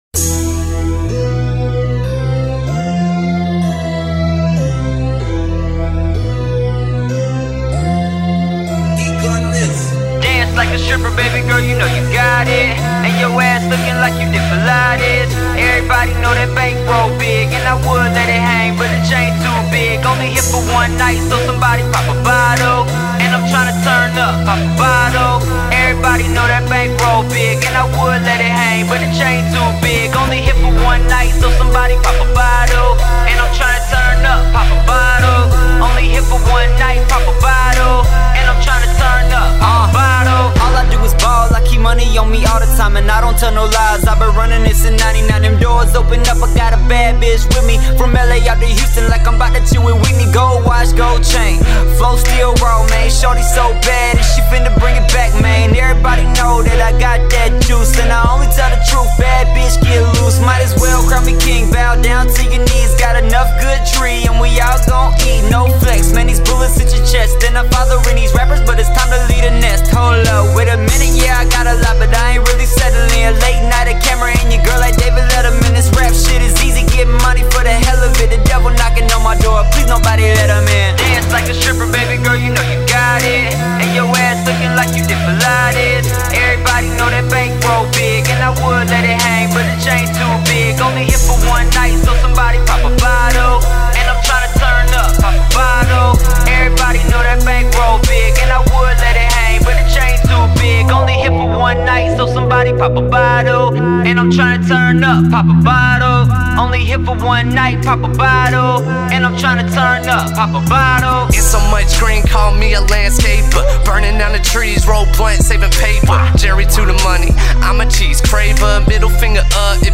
это зажигательный трек в жанре хип-хоп